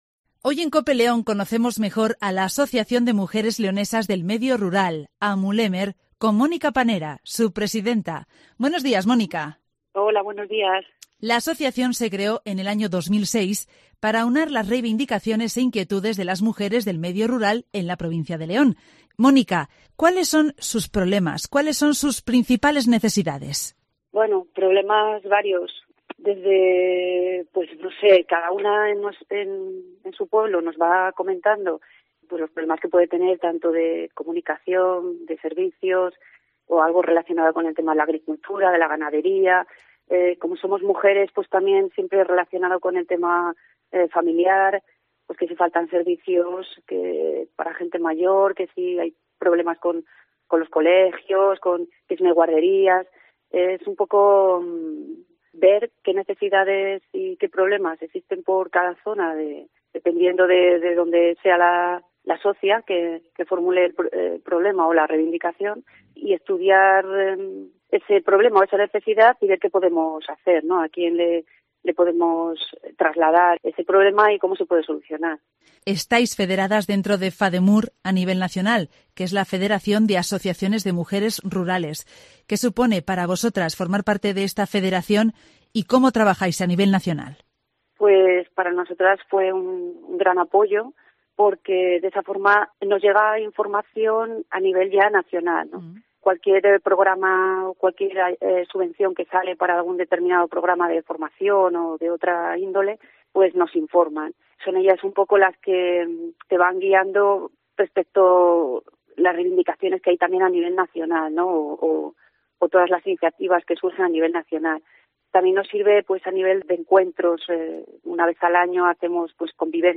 95.3 FM y 1.215 OM